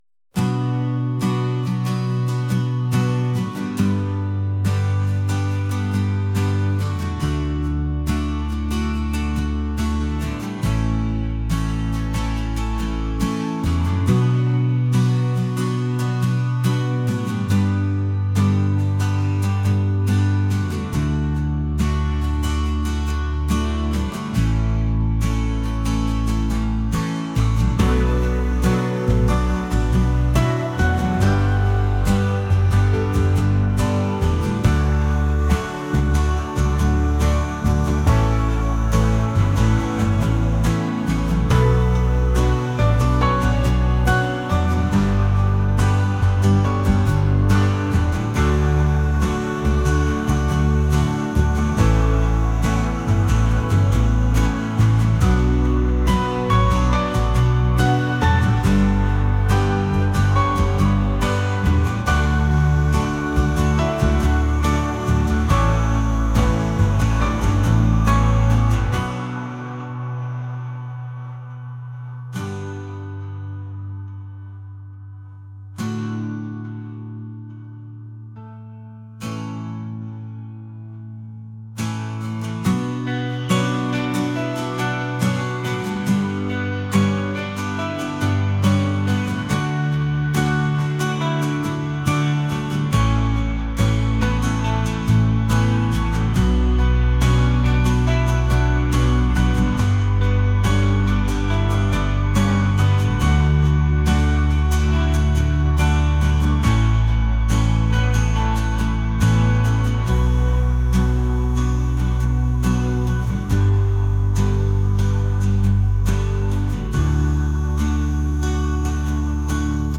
acoustic | indie | rock